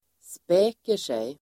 Ladda ner uttalet
Uttal: [sp'ä:ker_sej]